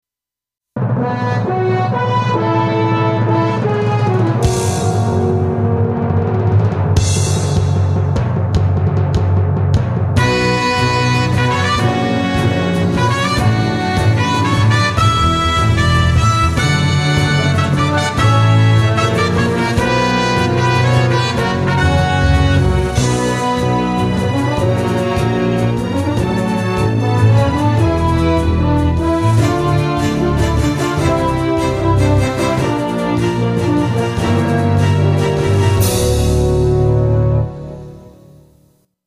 主な変更点：内声，ミックス
・冒頭のノイズをカット。
前半、ホーン部の主旋律にホルンの和声を重ねたのと、弦ではチェロに暴れてもらって（笑）厚みを出してみました。
今回は小さいホールをイメージした音作りに心がけてみました。
今回は、前回使っていたエレキベースをやめました。